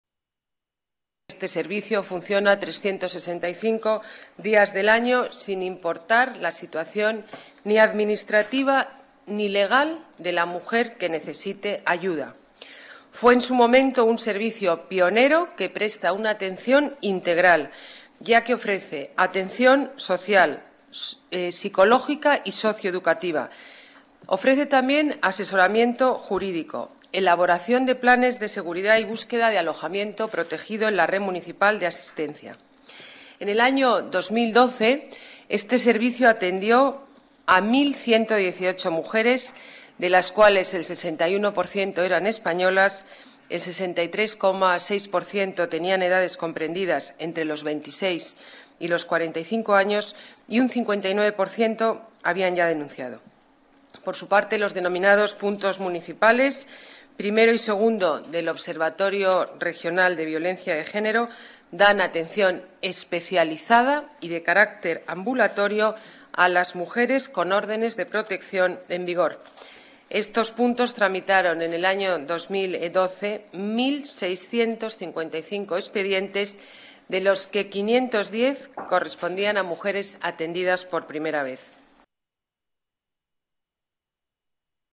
Nueva ventana:Declaraciones alcaldesa Madrid, Ana Botella: atención víctimas violencia género